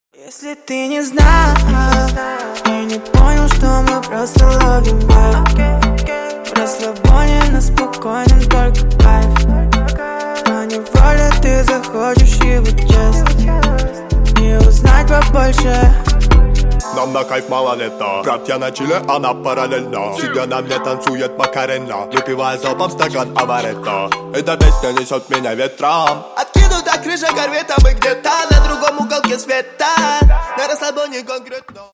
Громкие Рингтоны С Басами
Рэп Хип-Хоп